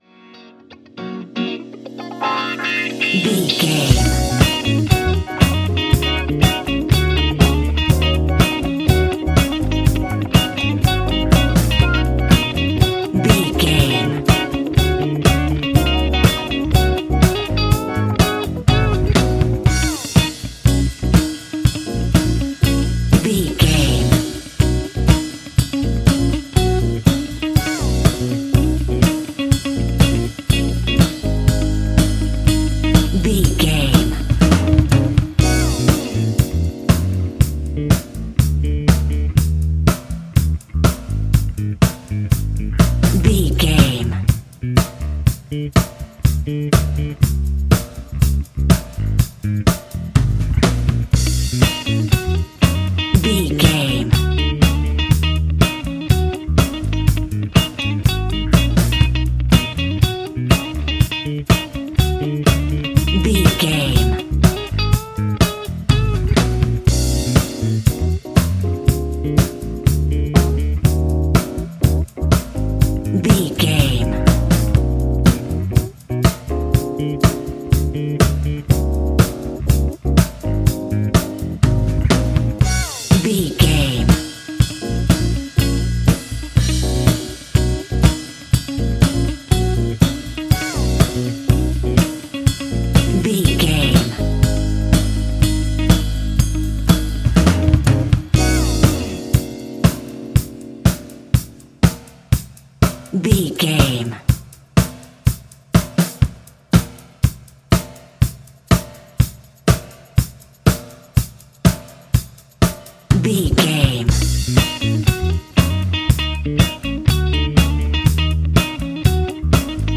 Ionian/Major
A♯
house
electro dance
synths
techno
trance
instrumentals